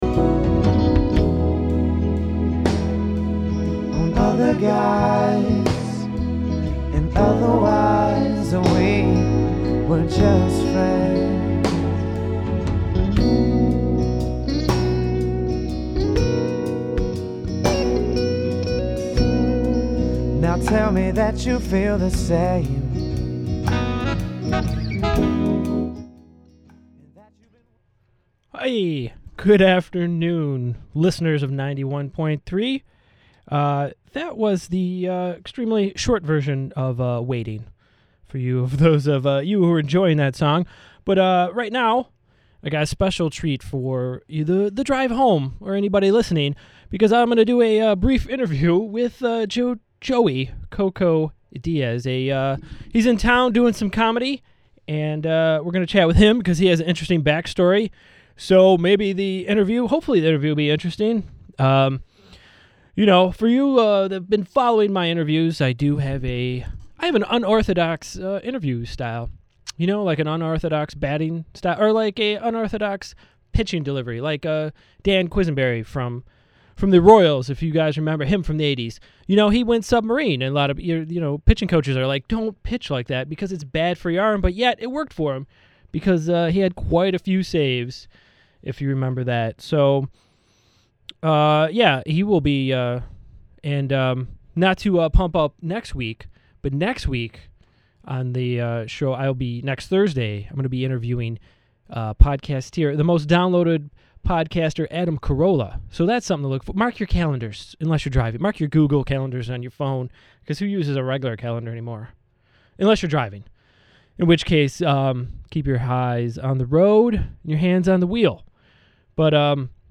comedy interview